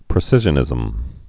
(prĭ-sĭzhə-nĭzəm)